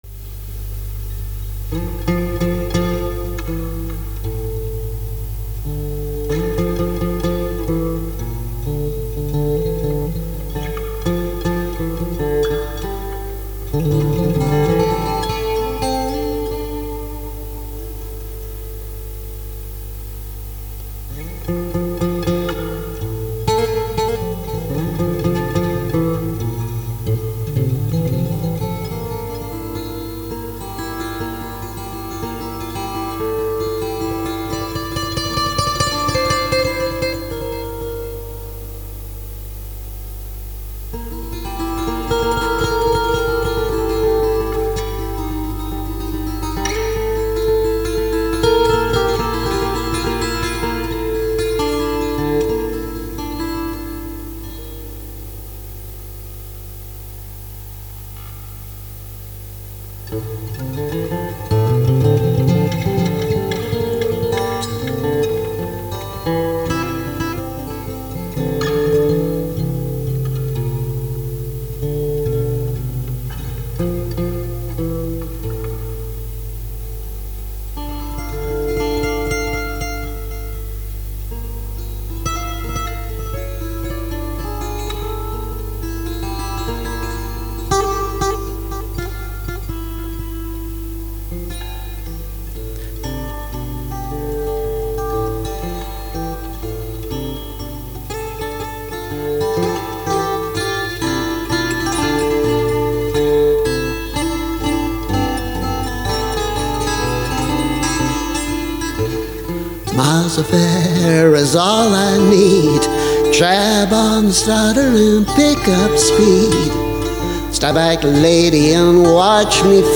This is a very young, very bitter song. I was actually playing with it in Garageband recently as a guitar piece, but the words came back to haunt me.
(Vocal is a bit ropey: heavy cold…)